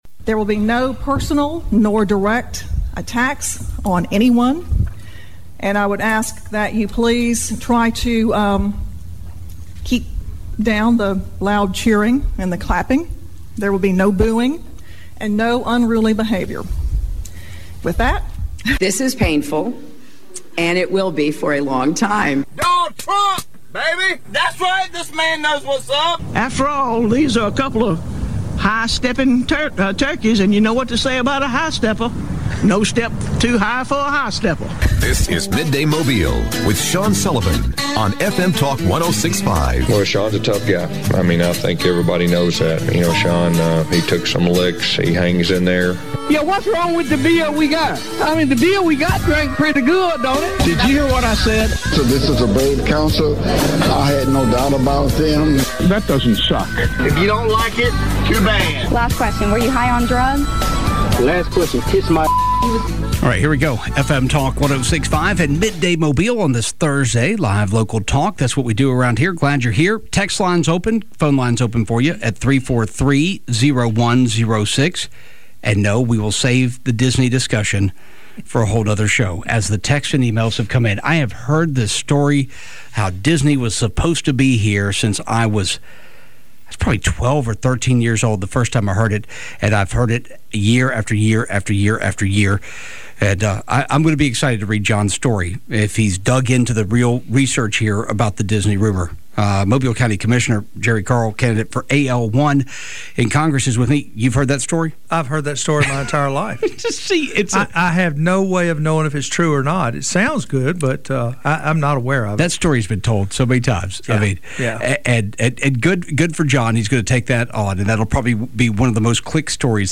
Jerry Carl is in studio. Jerry is a Mobile County commissioner currently running for the AL-1 congressional seat.